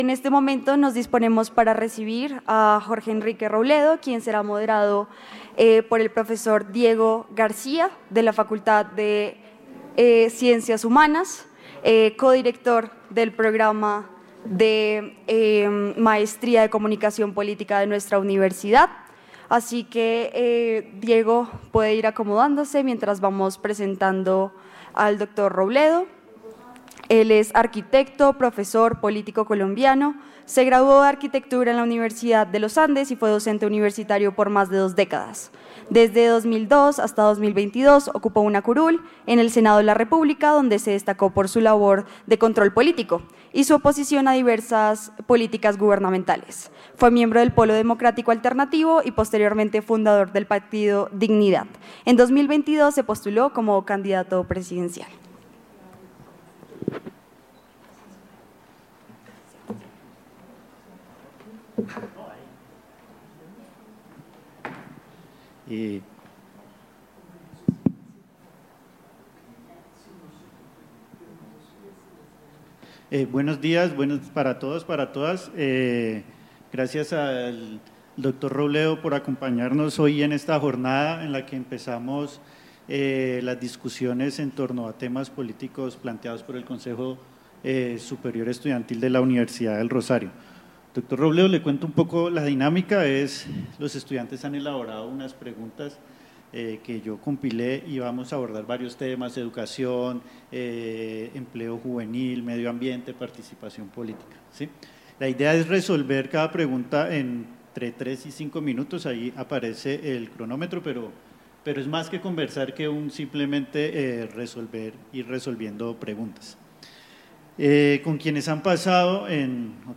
Jorge Enrique Robledo, ex senador, les responde a los jóvenes de la Univerisad del Rosario sobre temas en el evento ¿Qué país sueñan los jóvenes?, llevado a cabo en el auditorio Jockey el martes 29 de abril de 2025.